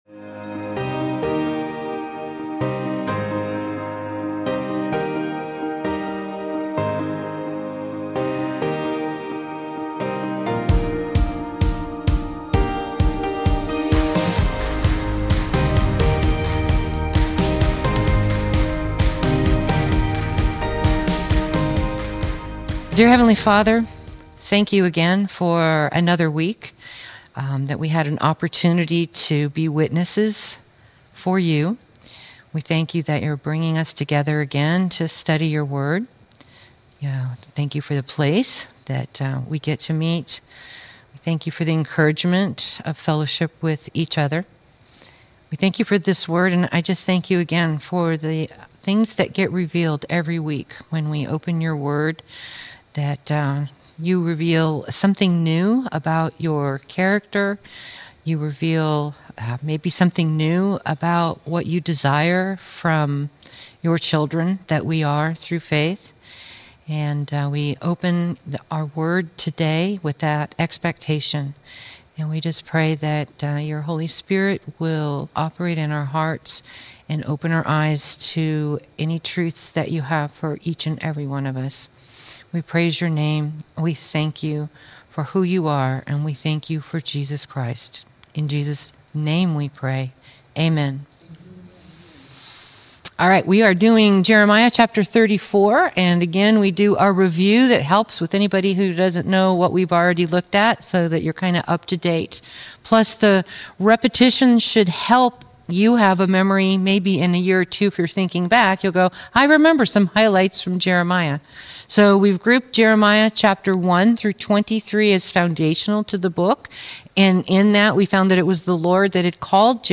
Jeremiah - Lesson 34 | Verse By Verse Ministry International